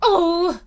peach_doh.ogg